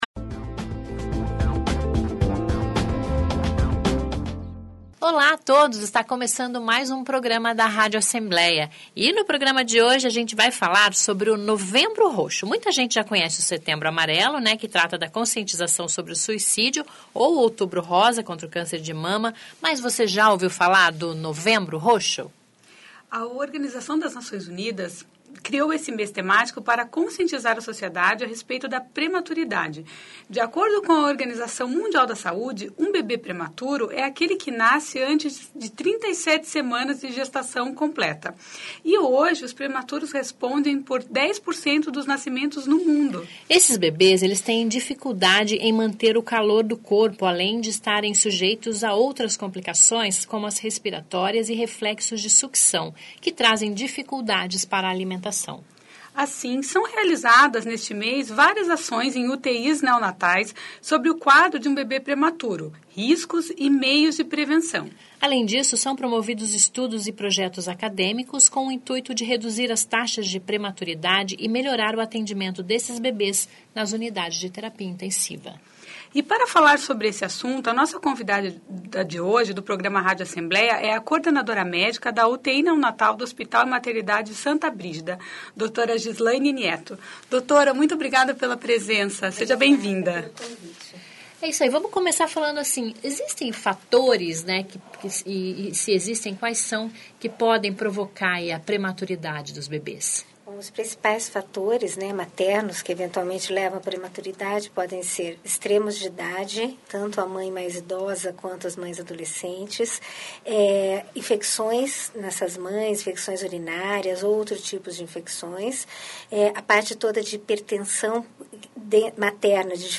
A convidada do programa Rádio Assembleia desta terça-feira (27) fala sobre o Novembro Roxo. A Organização Mundial da Saúde definiu esse mês temático para falar sobre os riscos da prematuridade.